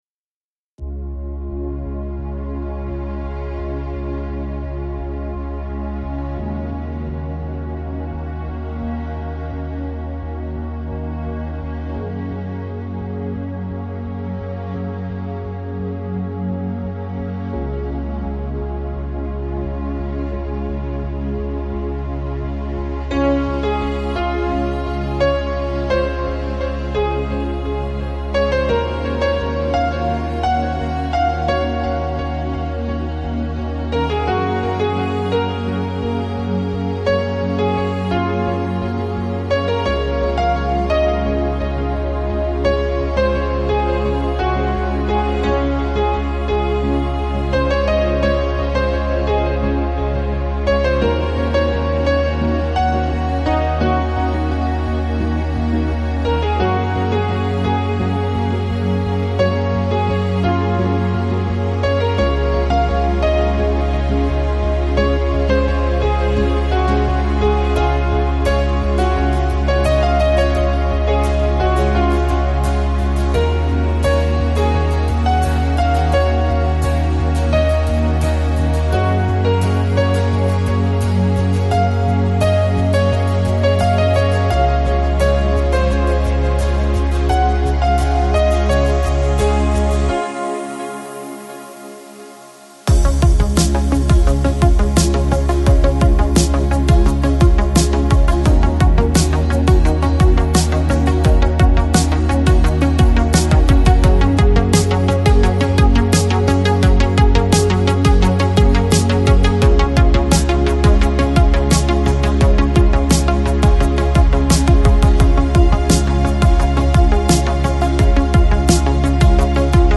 Chill Out, Lounge, Downtempo, Balearic Год издания